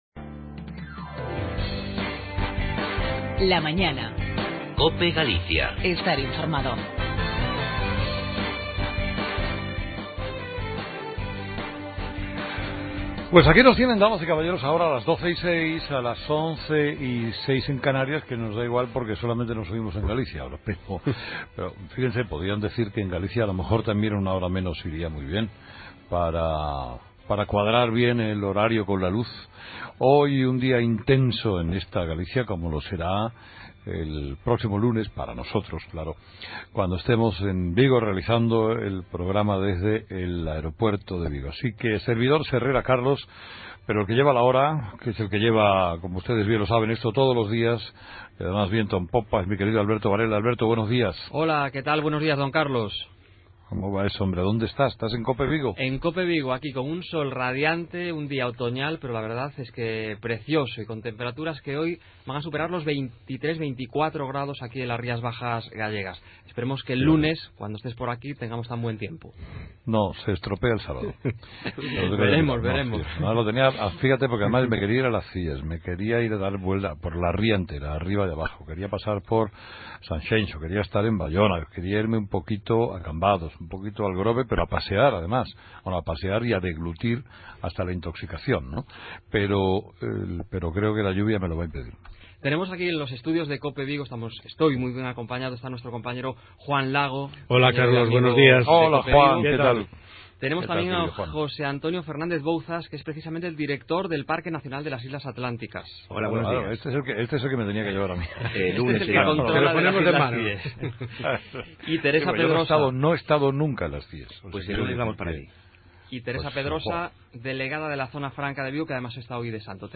AUDIO: Programa regional realizado desde los estudios de Cope Vigo con Carlos Herrera